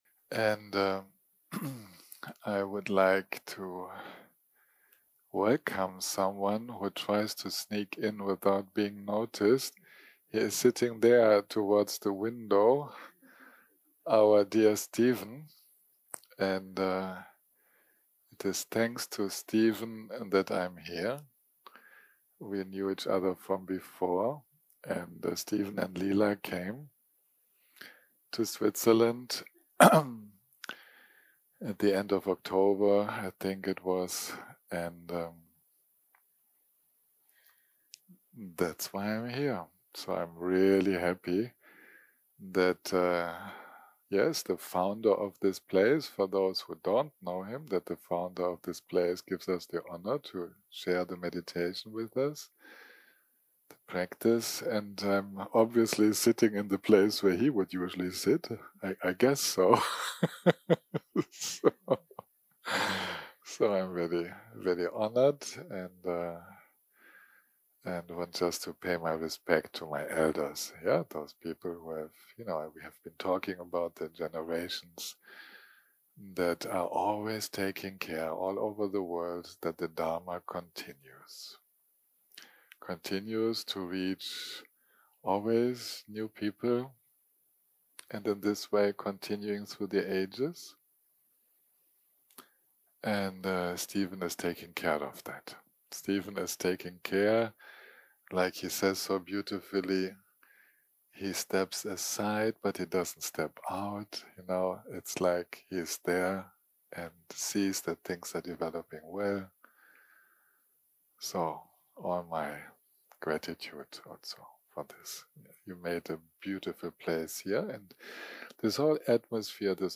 יום 7 - הקלטה 31 - בוקר - שיחת דהרמה - Emotions and compassion
שיחות דהרמה שפת ההקלטה